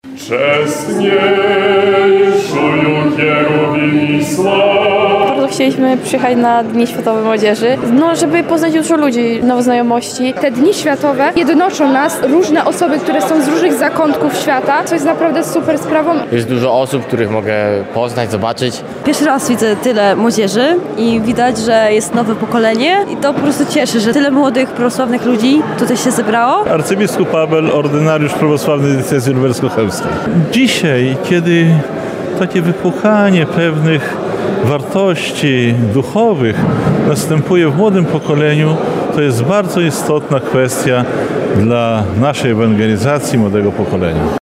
ZOBACZ ZDJĘCIA: – Dzisiaj, kiedy takie wypłukanie pewnych wartości duchowych następuje w młodym pokoleniu, to jest bardzo istotna kwestia dla naszej ewangelizacji młodego pokolenia – mówi arcybiskup Abel, ordynariusz prawosławny diecezji lubelsko-chełmskiej.